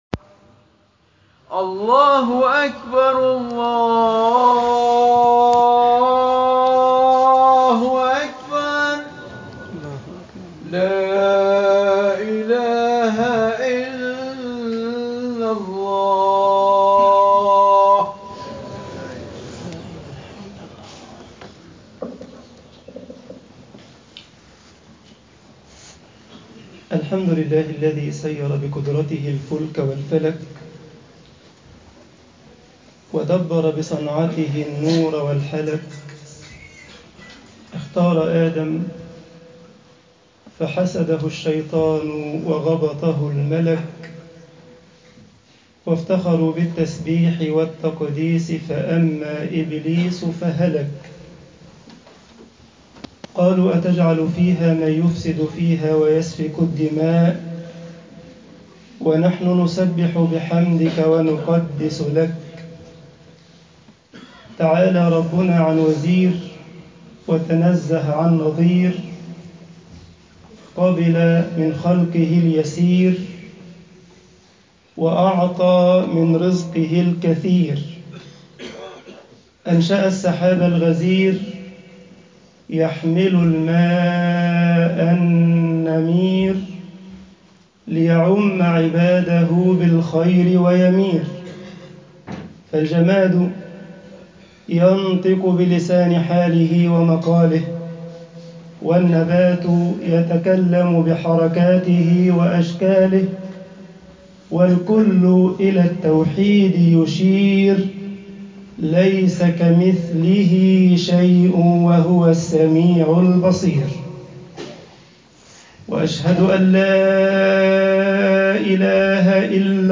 خطب الجمعة والعيد